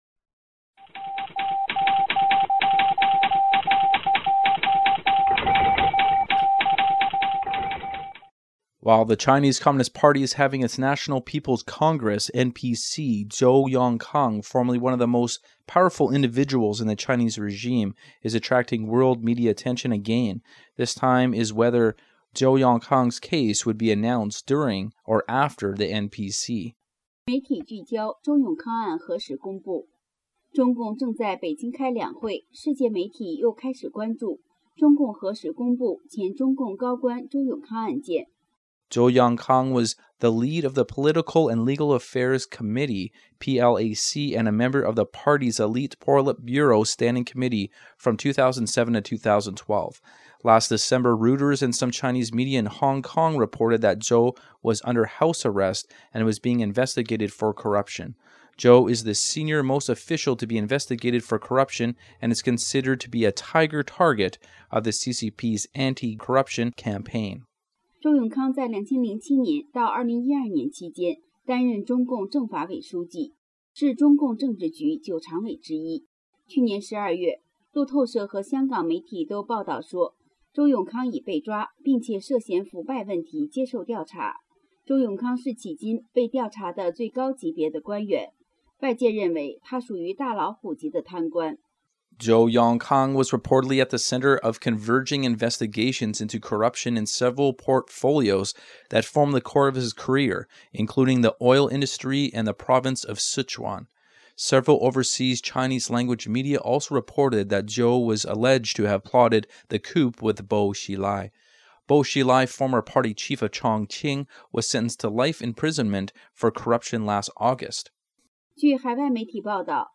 Type: News Reports
128kbps Stereo